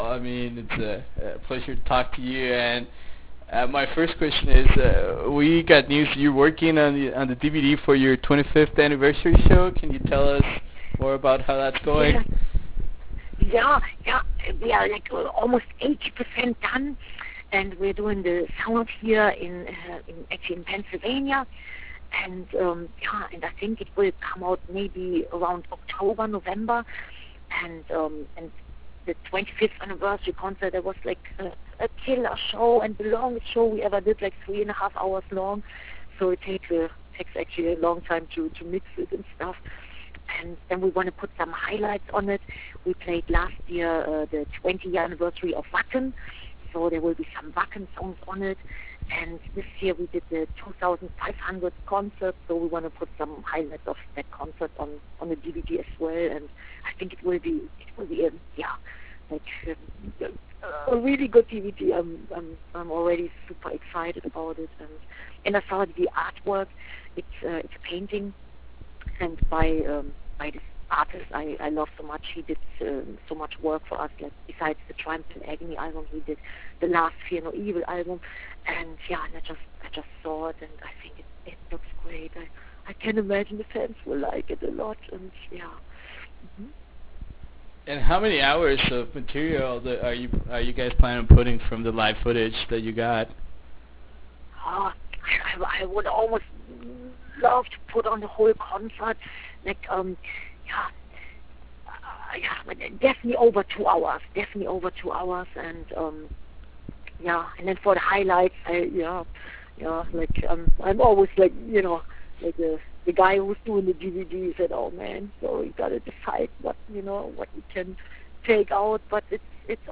Interview with Doro Pesch
Interview with Doro Pesch.wav